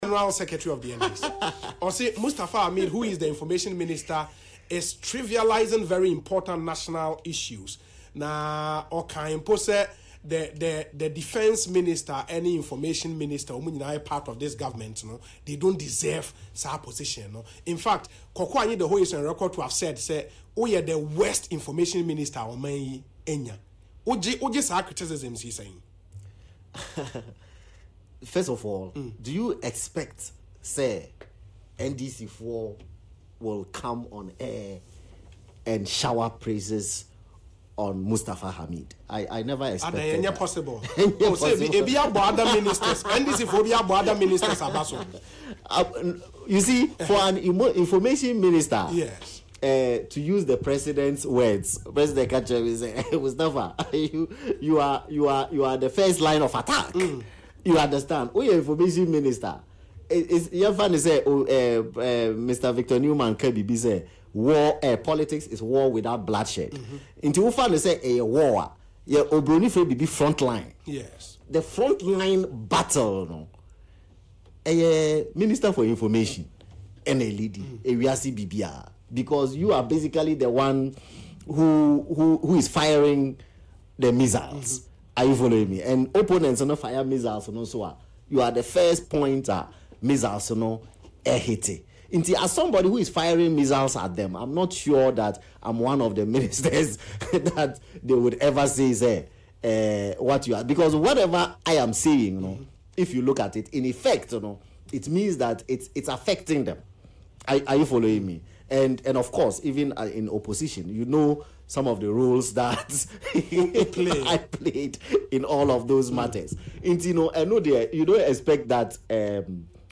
“If Koku Anyidoho calls me incompetent, I will take it as criticism and will work harder to improve. Tell Koku I will improve and make him happy”,  he said on Asempa FM’s Ekosii Sen political talk show on Wednesday.